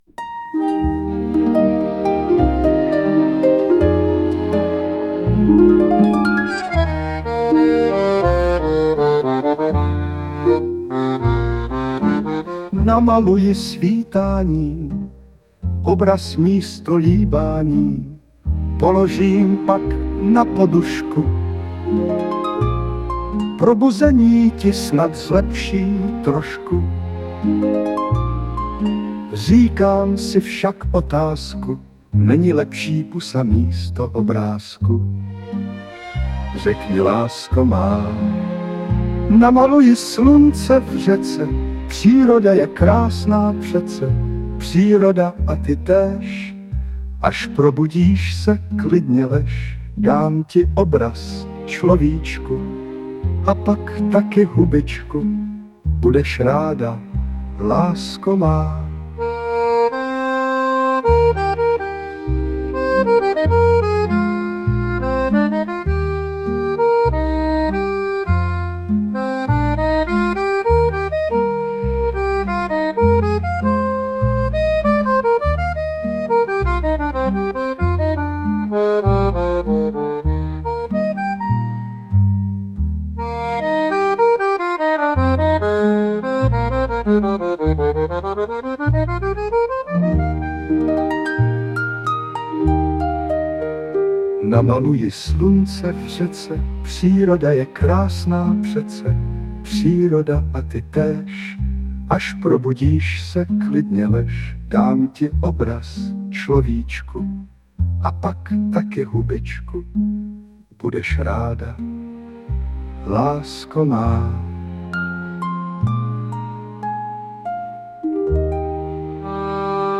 hudba, zpěv, obr.: AI